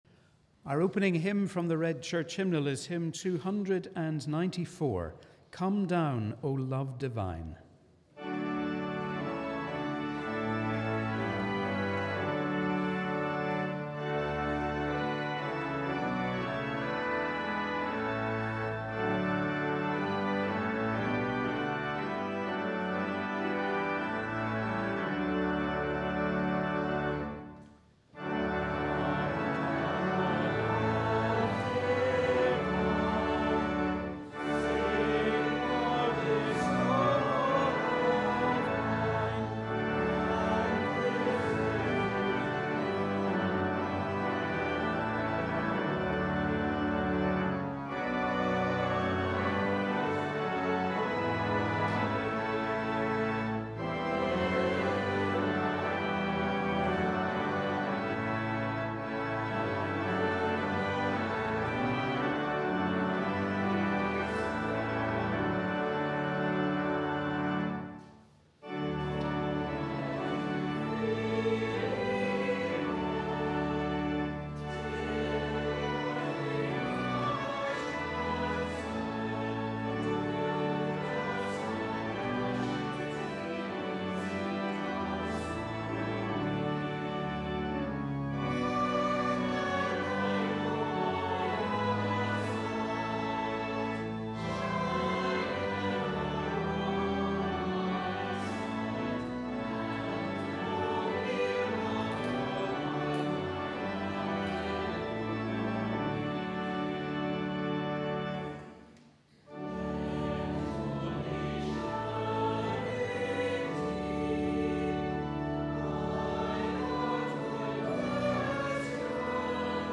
Welcome to our service of Wholeness and Healing on the 5th Sunday in Lent.